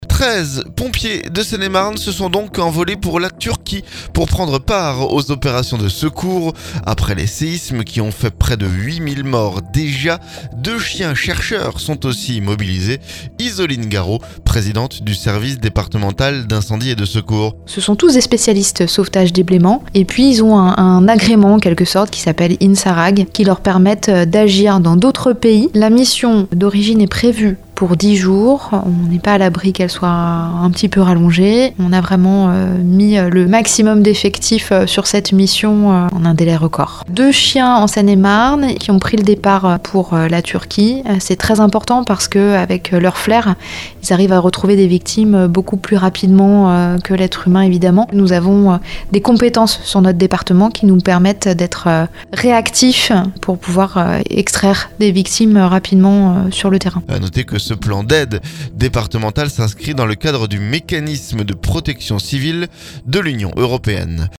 On en parle avec Isoline garreau, Présidente du service départemental d'incendie et de secours.